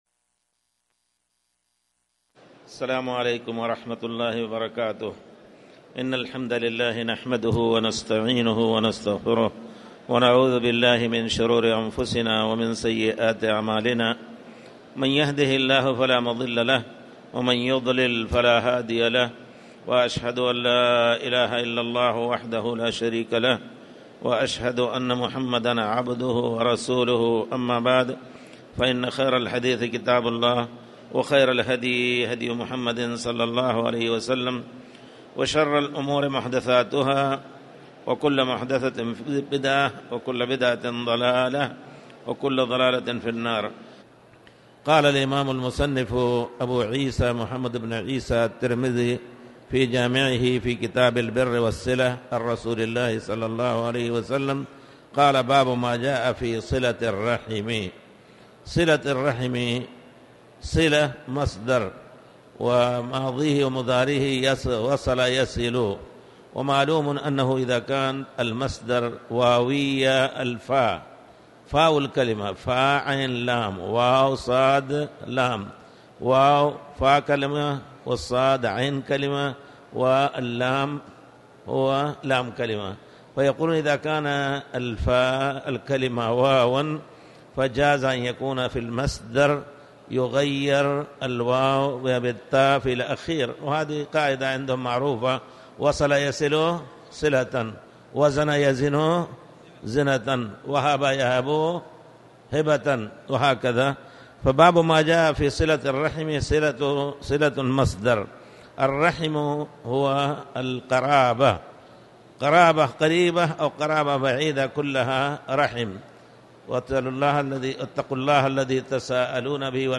تاريخ النشر ٦ ذو القعدة ١٤٣٨ هـ المكان: المسجد الحرام الشيخ